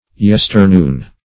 Yesternoon \Yes"ter*noon`\, n. The noon of yesterday; the noon last past.